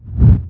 tl_ring_whoosh.wav